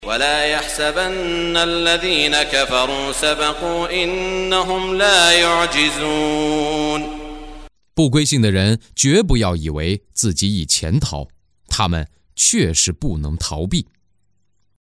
中文语音诵读的《古兰经》第（安法里）章经文译解（按节分段），并附有诵经家沙特·舒拉伊姆的朗诵